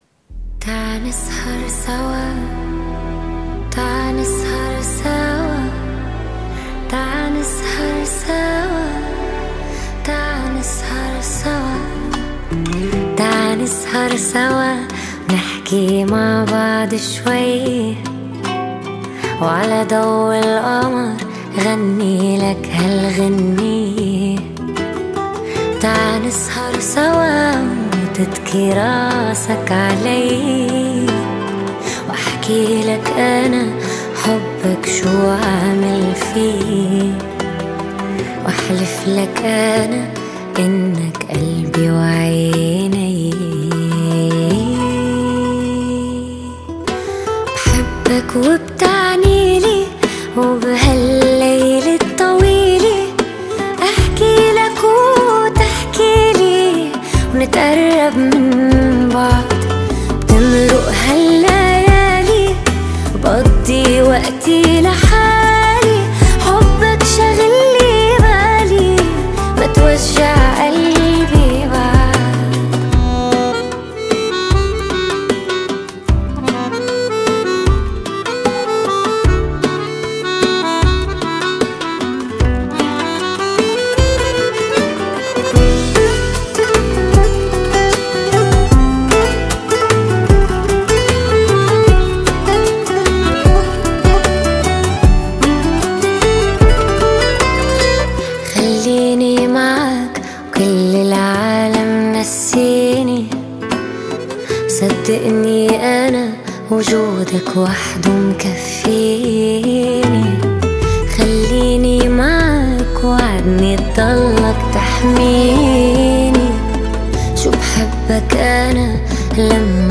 النوع بوب لبناني رومانسي